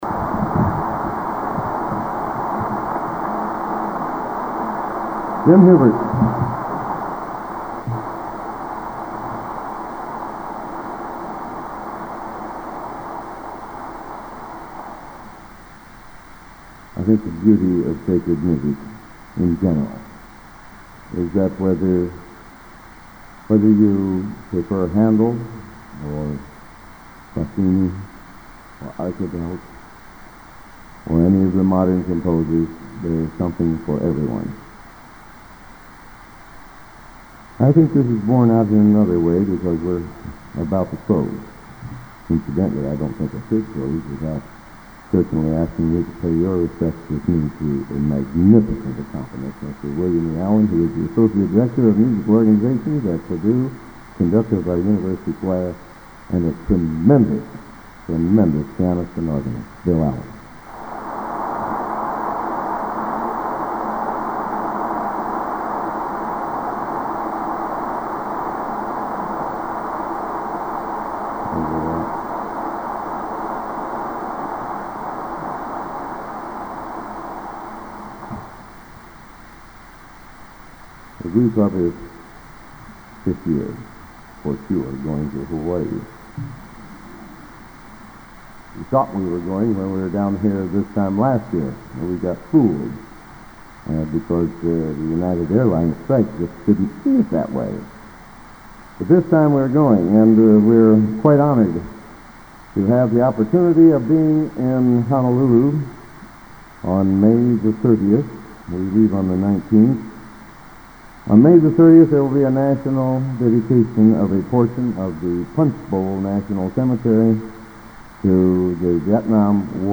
Genre: | Type: Director intros, emceeing